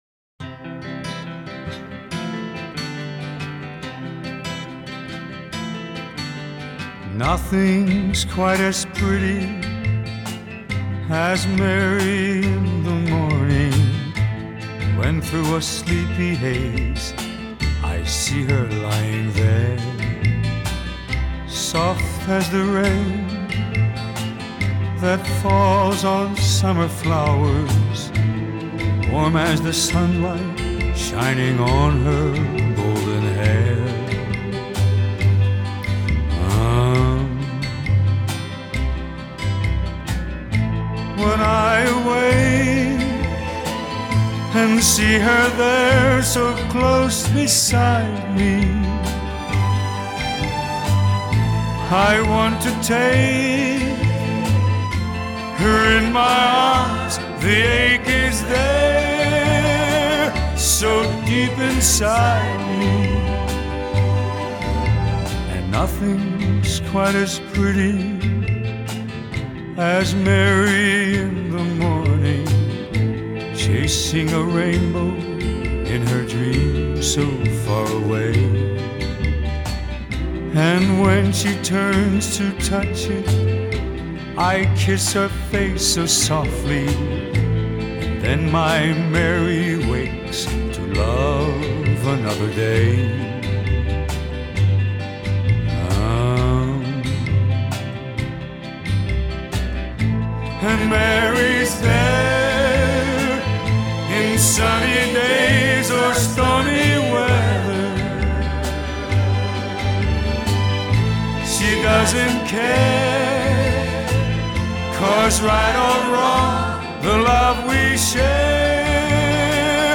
romántica melodía